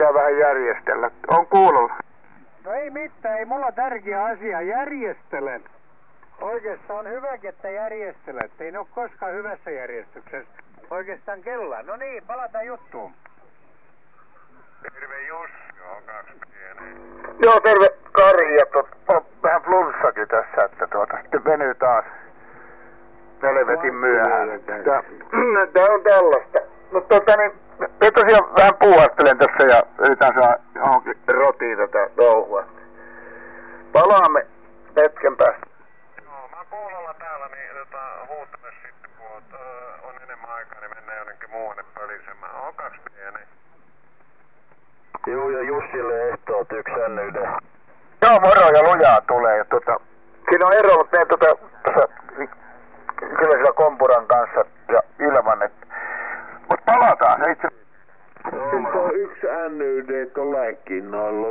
Recordings are done with wide test filters loaded in the AFEDRI8201 chip. Recording location is Espoo Finland and antenna is 80m dipole low in the bushes. MP3 samples are left channel only.
After fir2 we have Hilbert filters with pass band from about 100Hz to 3kHz.
MP3 recording from OH ham rag chewing frequency 3699kHz LSB